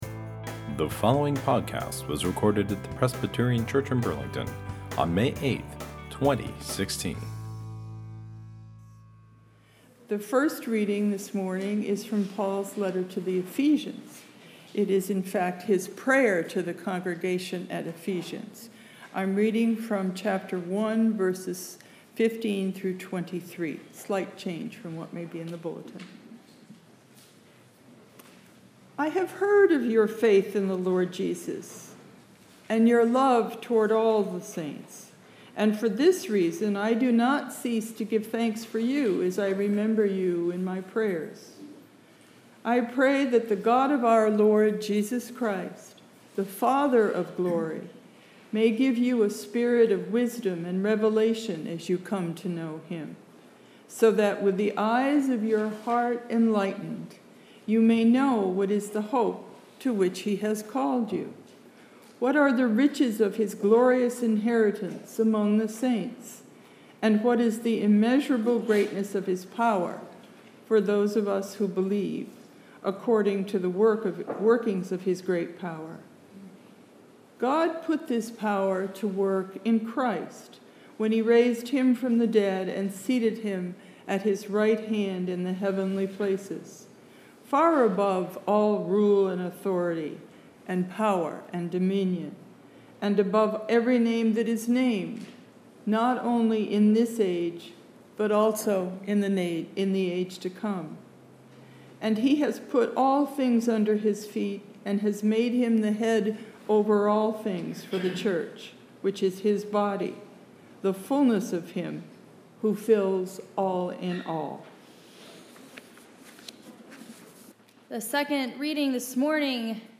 Sermon, May 8